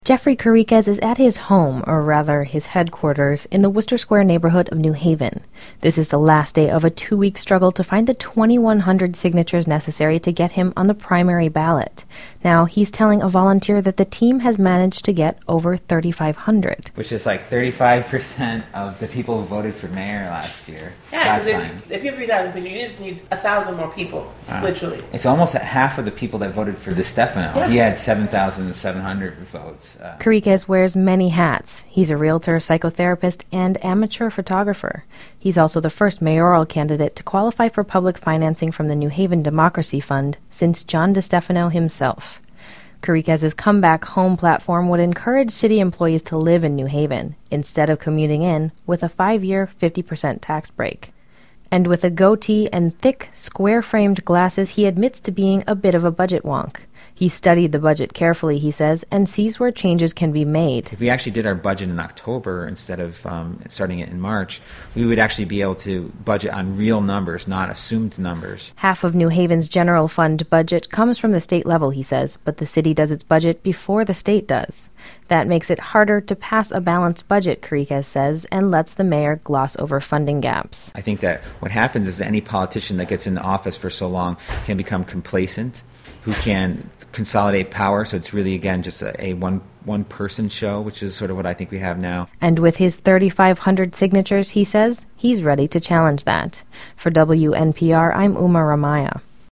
WNPR: Morning Edition Interview